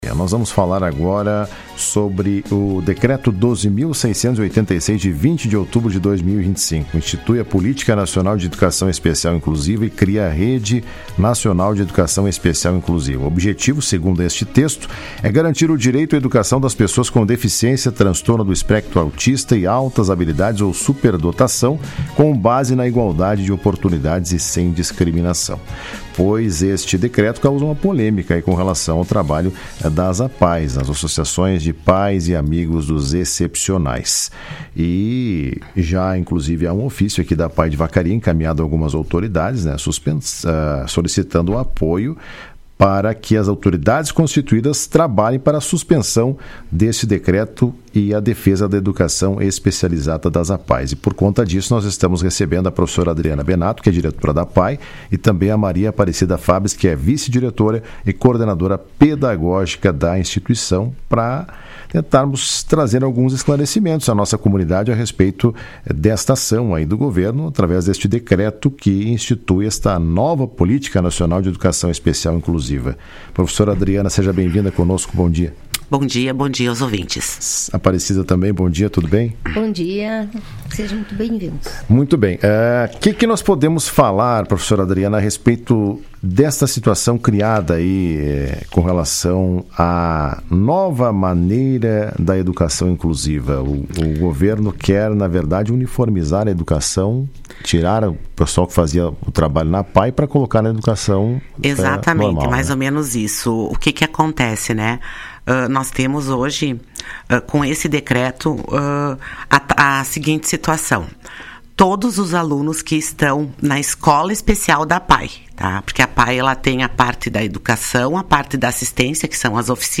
ENTREVISTA-APAE-11-11-MONTAR.mp3